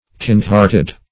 Kind-hearted \Kind"-heart`ed\, a.